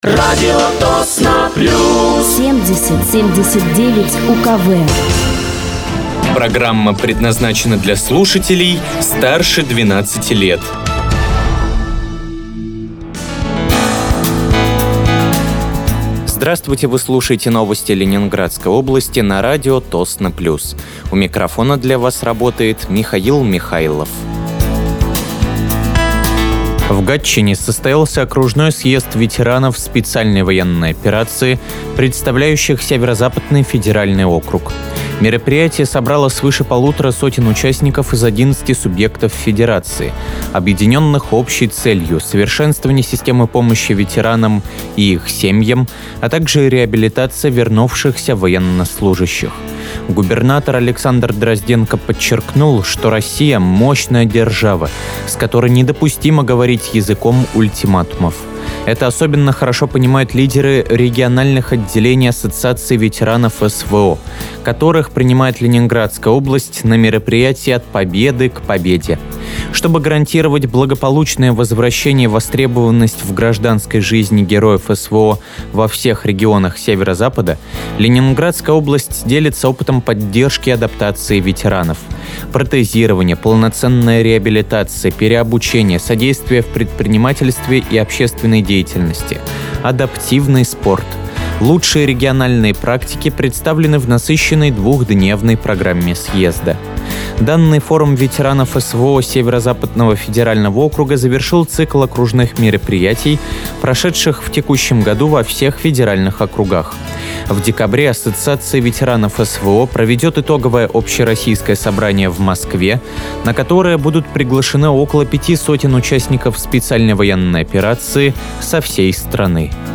Вы слушаете новости Ленинградской области на радиоканале «Радио Тосно плюс».